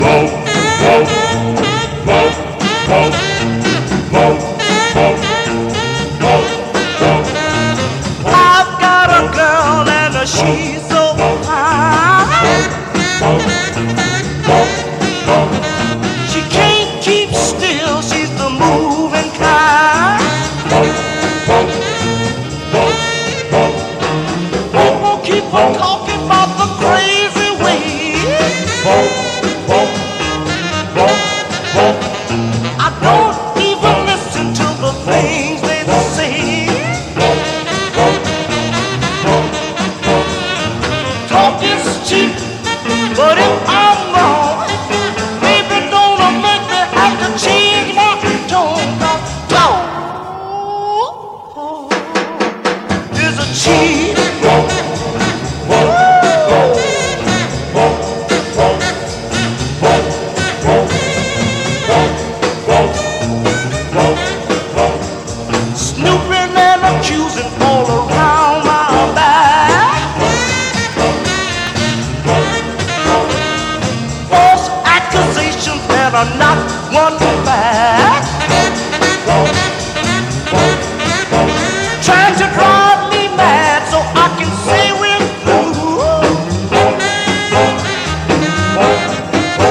洗練されていてダンサブル、時にメロウ。